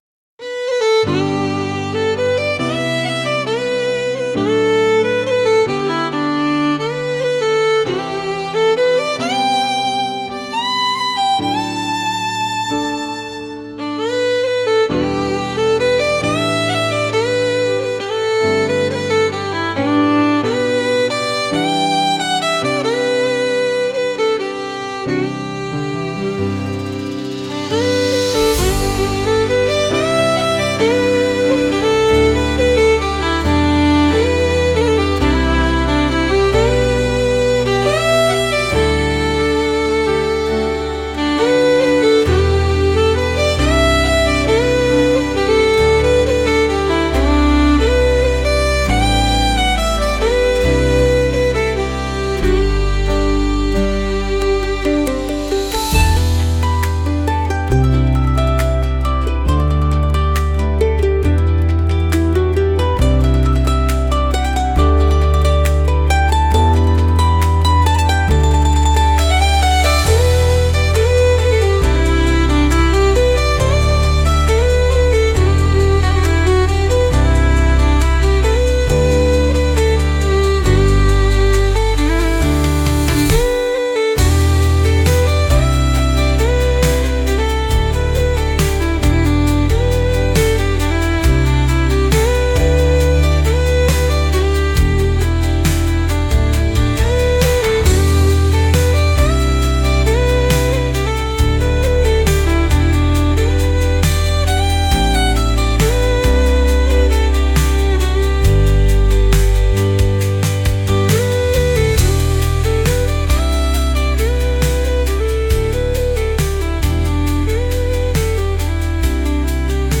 明日また逢おうという曲で少し切なさを含んだ曲です。